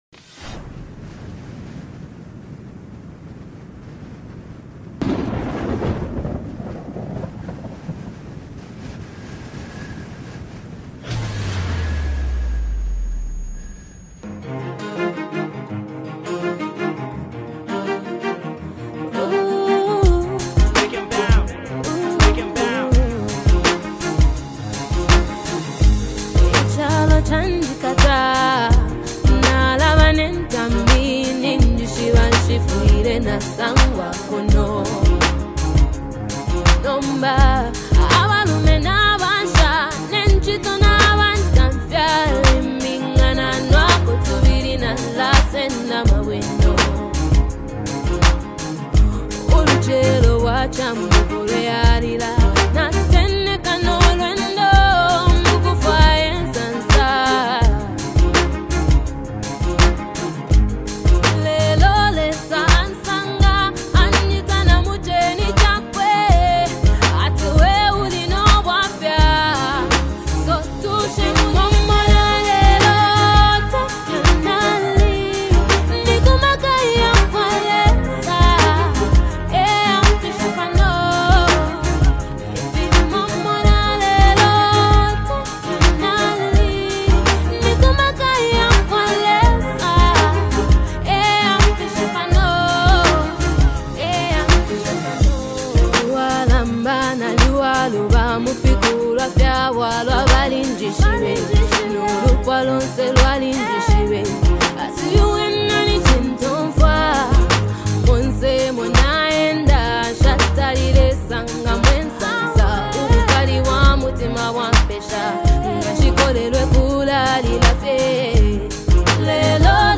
create an atmosphere of pure worship and thanksgiving
gospel music